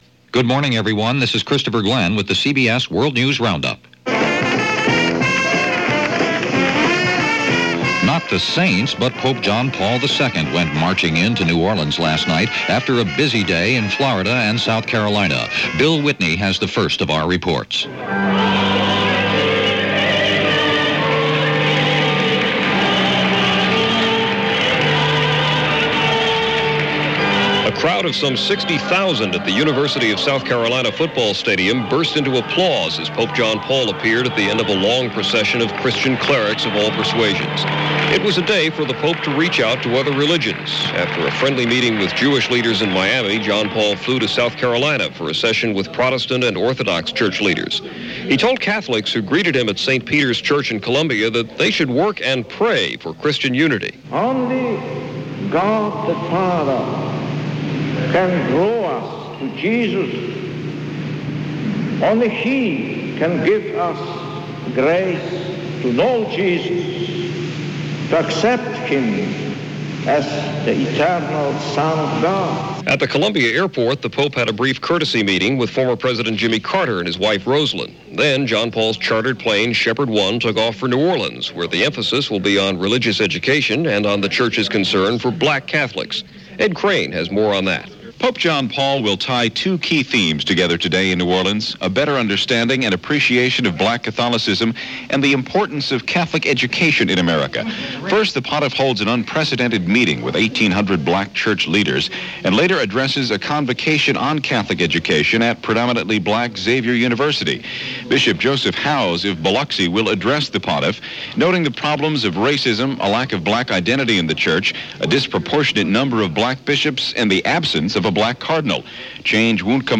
The Pope Visits New Orleans – to the tune of “When the Saints Go Marching In”.
And that’s a small slice of what went on in the world this September 12, 1987 as reported on The CBS World News Roundup.